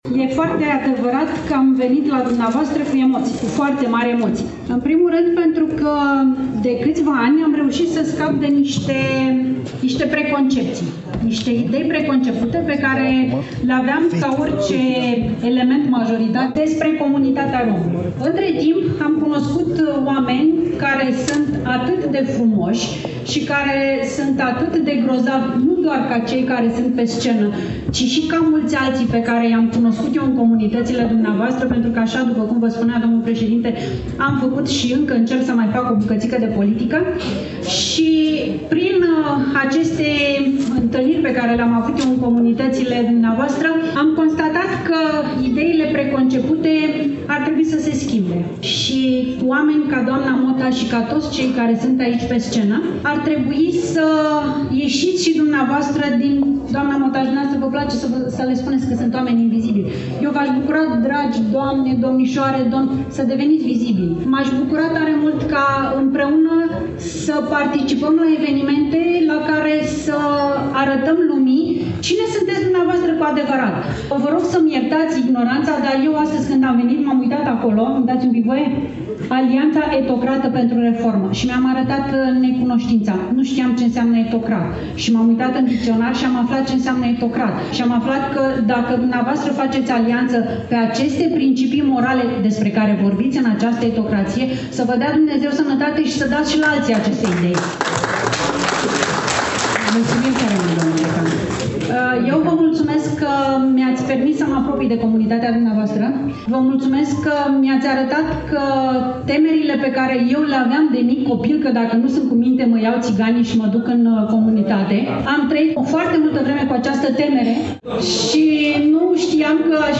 Dragi prieteni, la evenimentul de zilele trecute din incinta Ateneului Național Iași, am ascultat-o